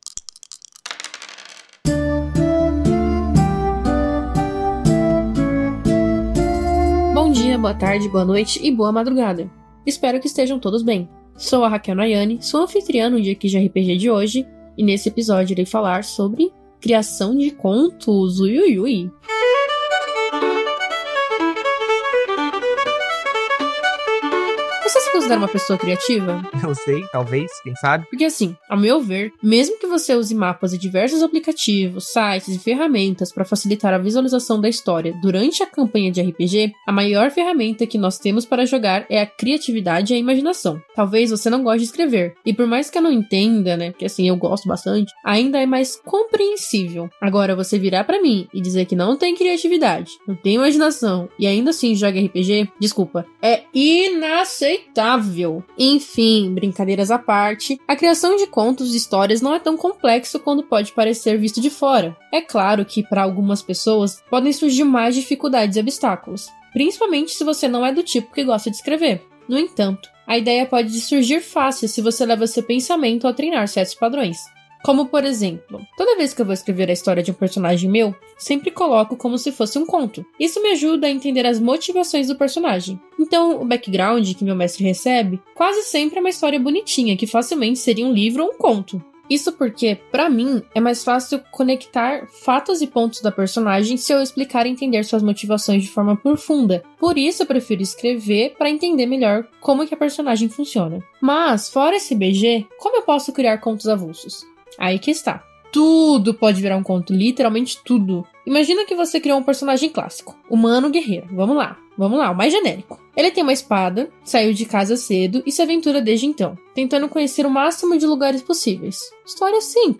O Dicas de RPG é um podcast semanal no formato de pílula que todo domingo vai chegar no seu feed.
Músicas: Music by from Pixabay